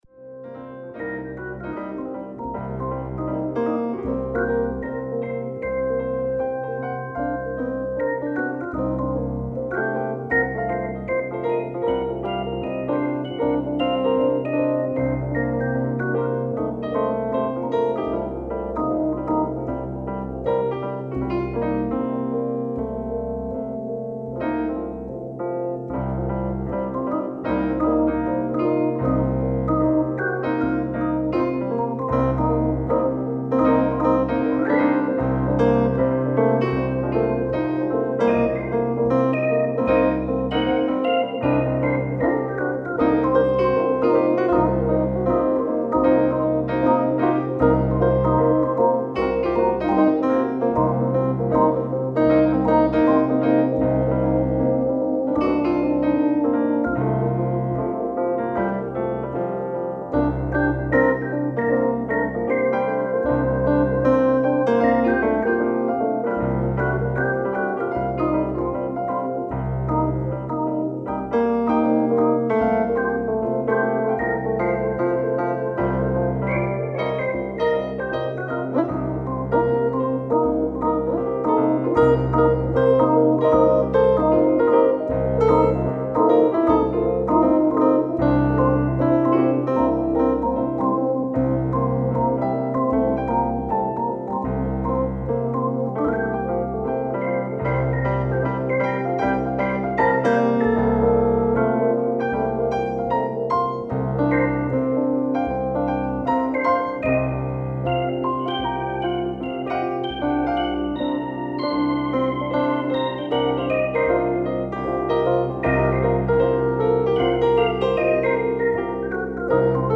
Tempo: 77 bpm / Datum: 10.09.2016